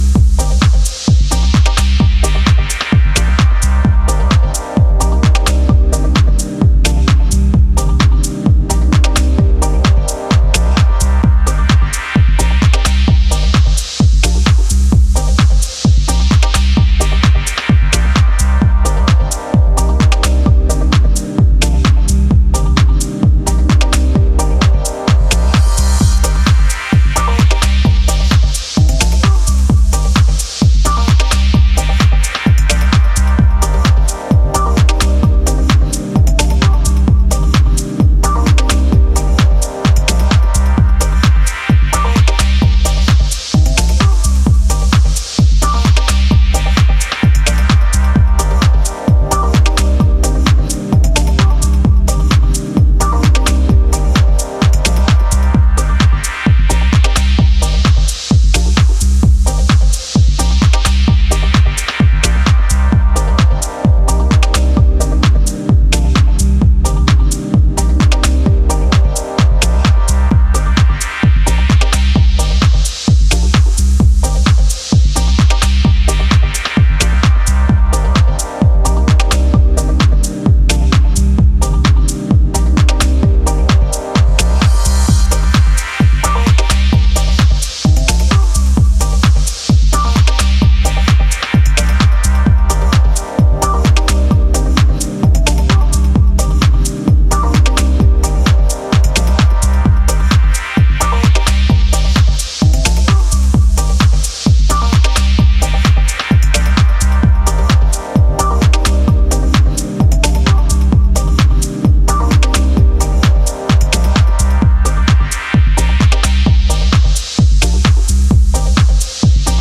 one of the most creative Techno producers in recent years